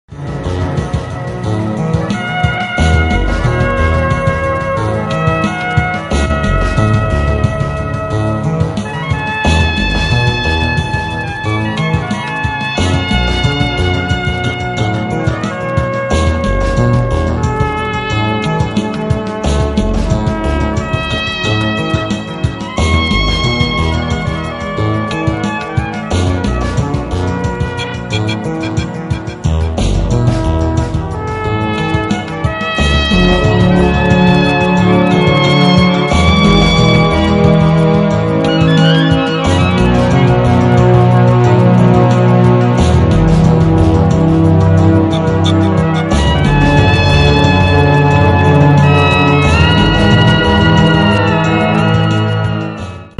early TD-style